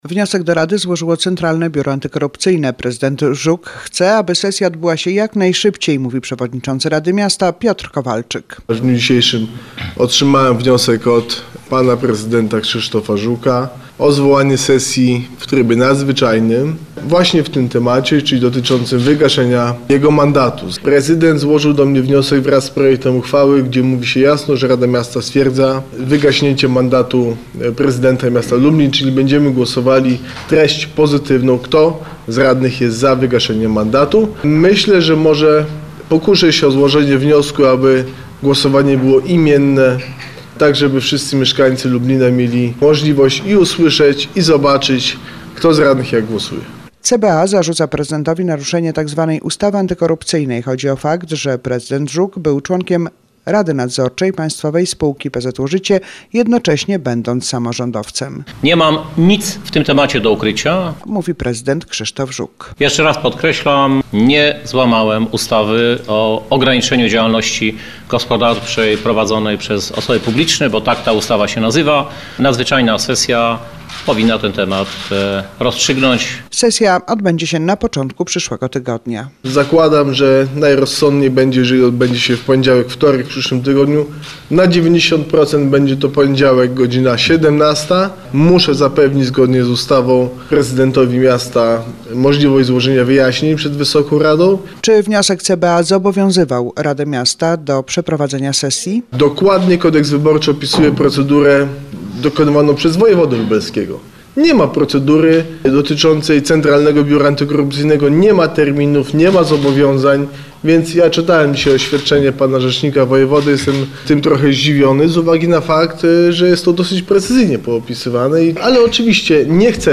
Nadzwyczajna sesja powinna tę sprawę rozstrzygnąć – mówił podczas konferencji prasowej prezydent Krzysztof Żuk.